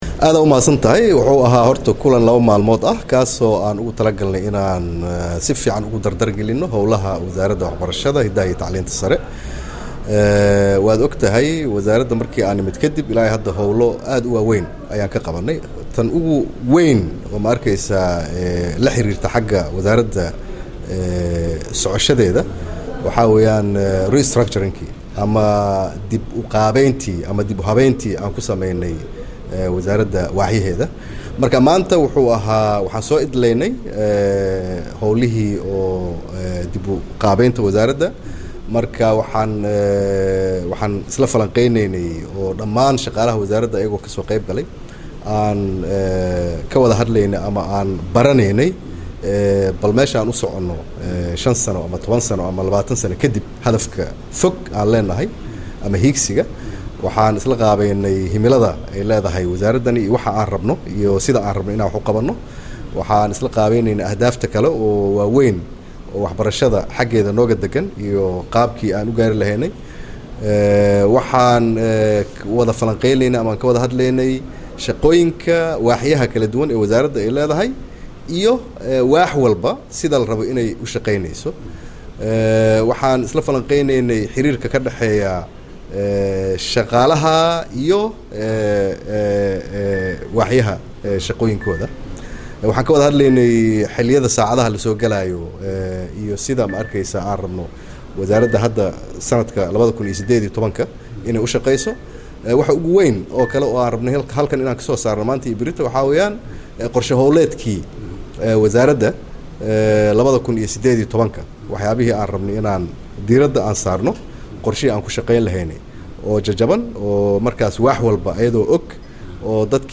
Wasiirka Wasaaradda Waxbarashada iyo Tacliinta sare ee Xukumadda Federaalka Soomaaliya Mudane C/raxmaan Daahir Cismaan oo la hadlay Warbaahinta Qaranka Soomaaliya ayaa sheegay in Wasaaradda Waxbarashada Xukumadda Soomaliya ay didaal ugu jirto xoojinta Waxbarashada Dalka.
Waraysi-Wasiirka-Waxbarashada-Cabdiraxmaan-Daahir-Cusmaan-.mp3